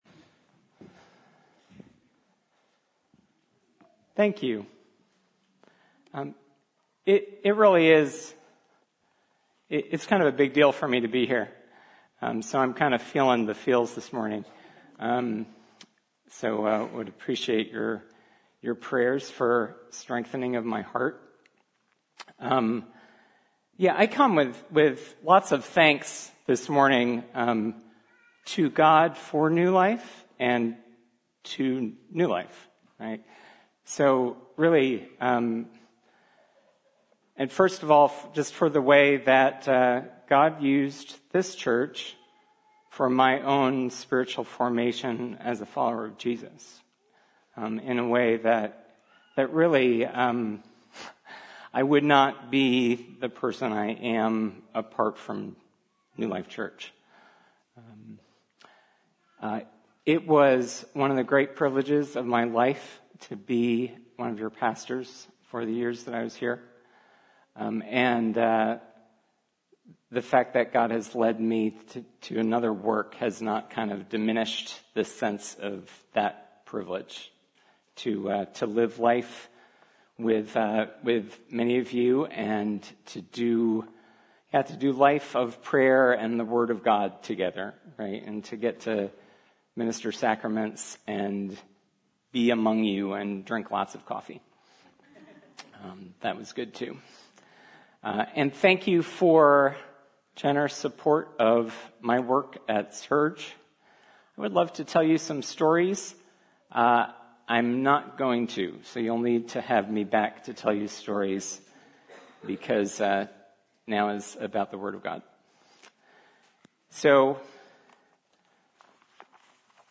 Sermons – New Life Glenside